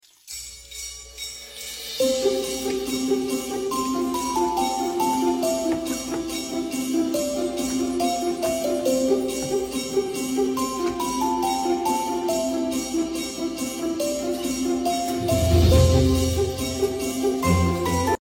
1133 Foley cooking using PopRocks, sound effects free download